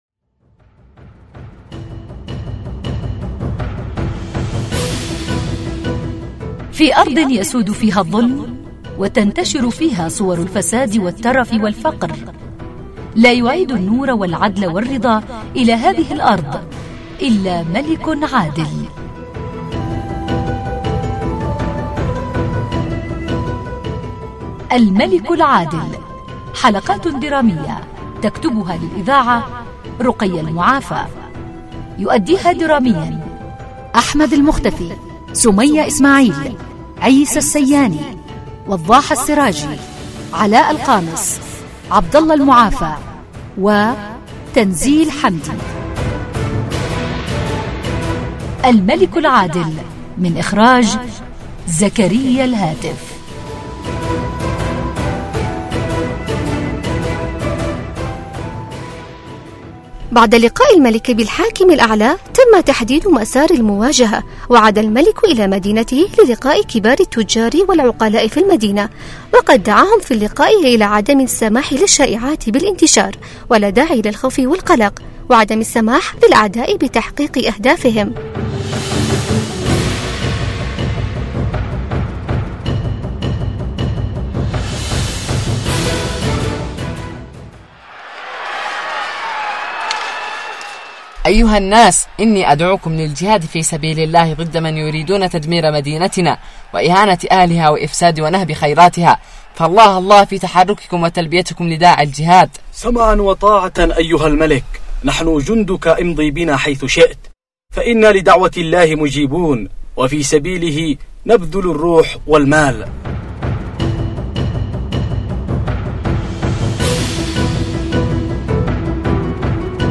برنامج درامي للأطفال يحكي عن الملك العادل